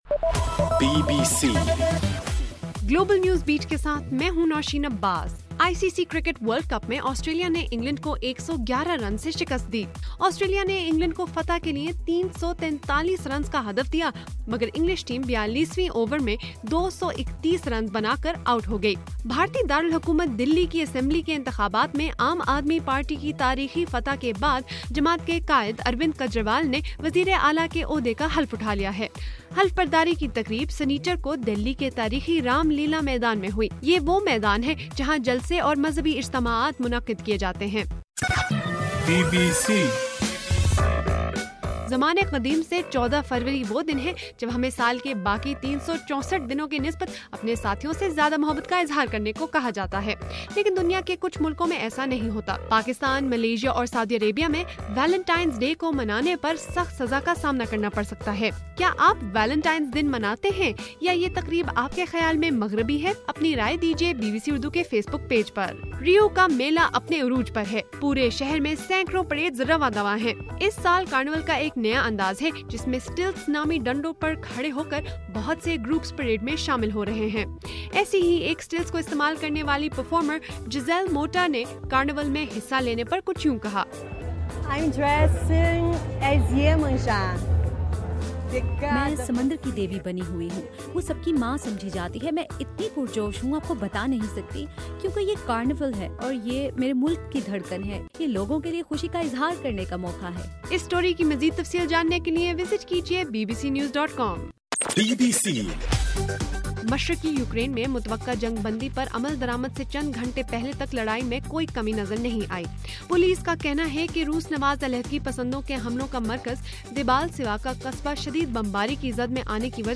فروری 14: رات 11 بجے کا گلوبل نیوز بیٹ بُلیٹن